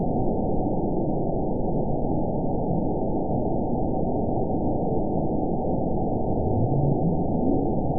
event 922304 date 12/29/24 time 05:26:40 GMT (11 months, 1 week ago) score 7.27 location TSS-AB04 detected by nrw target species NRW annotations +NRW Spectrogram: Frequency (kHz) vs. Time (s) audio not available .wav